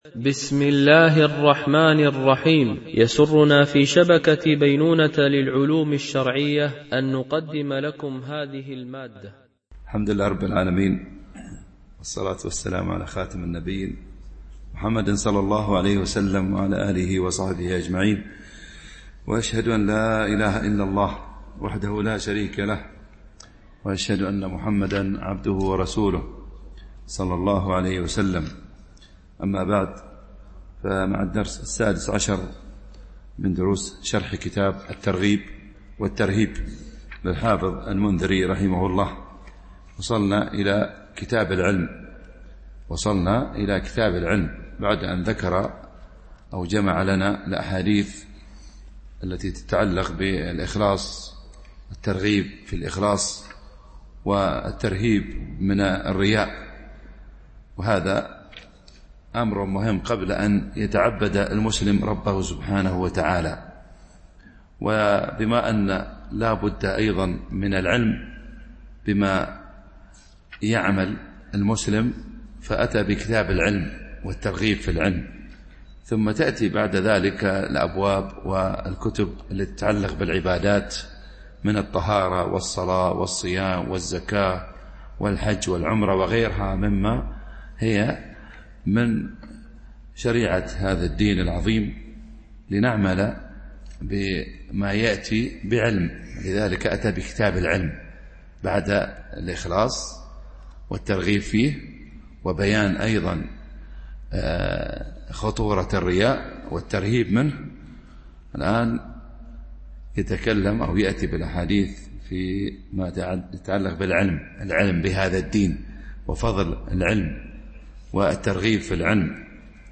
شرح كتاب الترغيب والترهيب - الدرس16 ( كتاب العلم .الحديث 102-106)